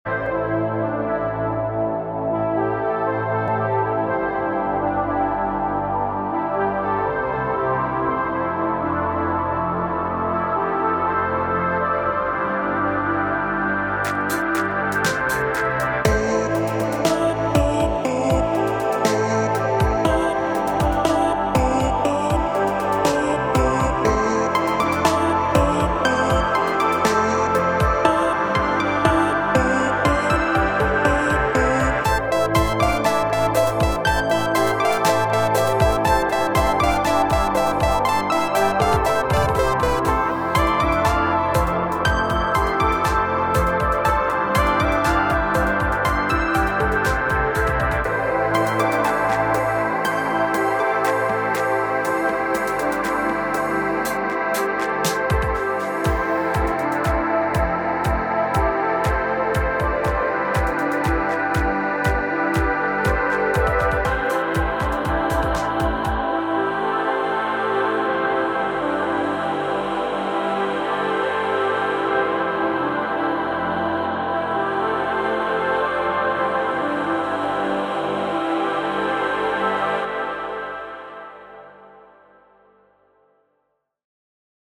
Home > Music > Beats > Bright > Smooth > Medium